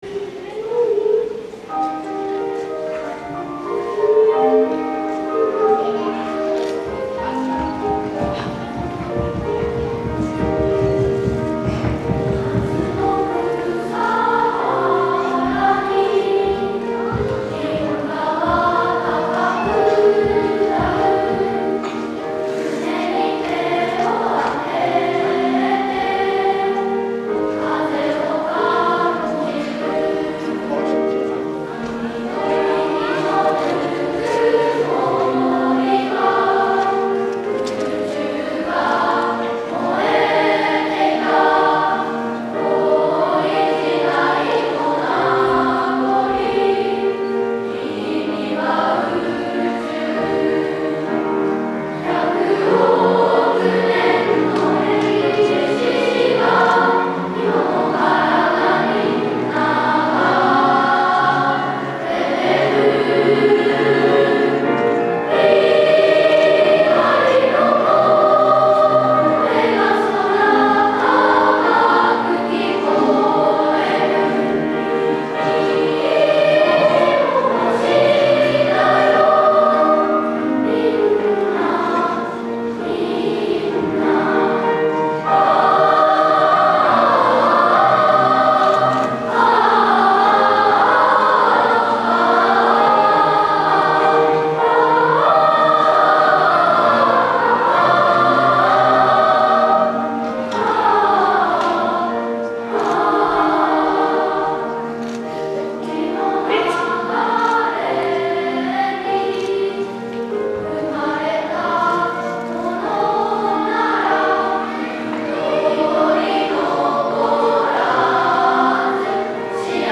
5・6年生（高学年チーム）　2部合唱「
２番のサビはアカペラで歌います♪
子どもたちの優しい歌声が会場いっぱいに広がりました！